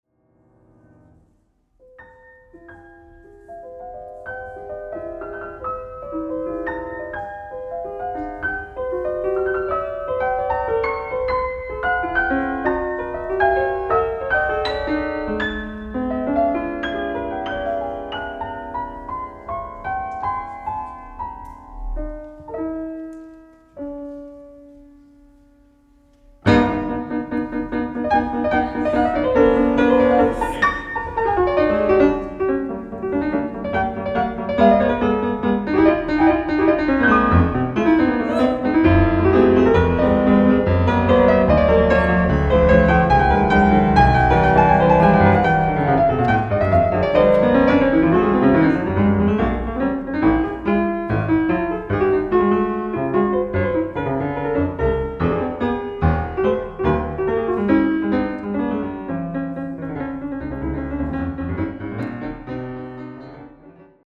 CONTRAST – solo piano (excerpts)